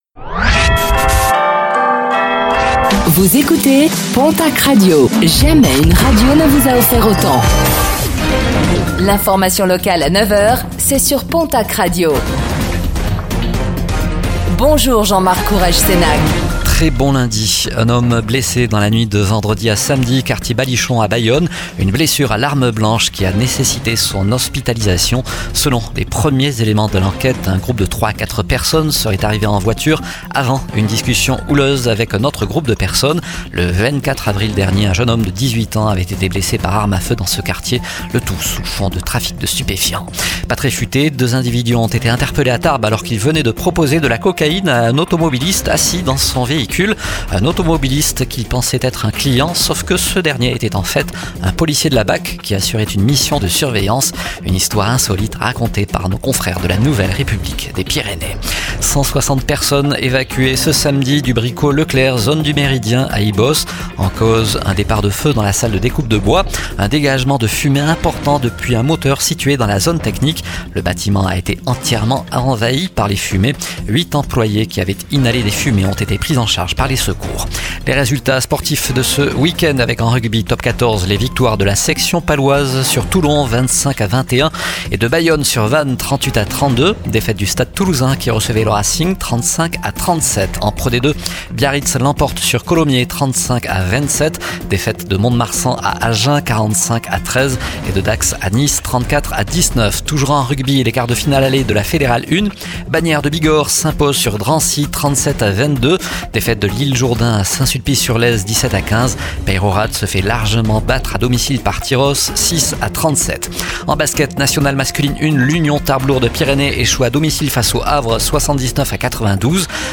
Infos | Lundi 19 mai 2025 - PONTACQ RADIO